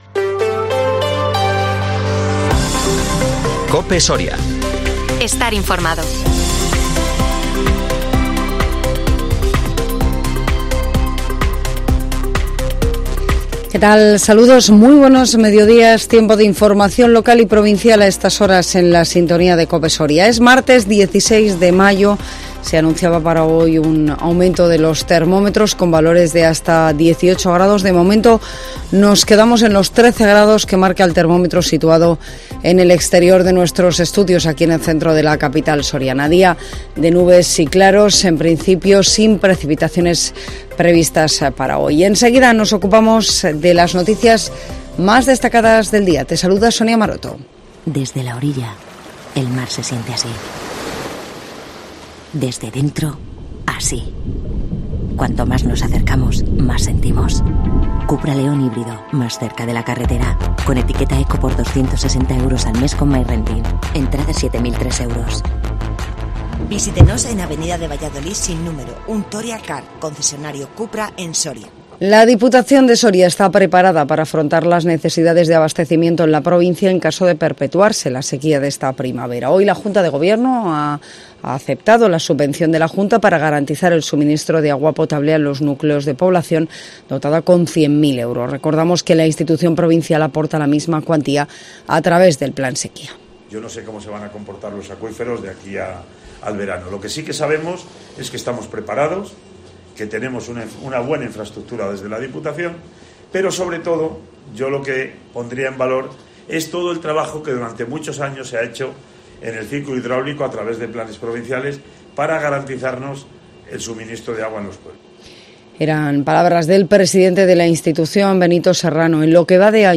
INFORMATIVO MEDIODÍA COPE SORIA 16 MAYO 2023